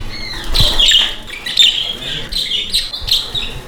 ptaki.mp3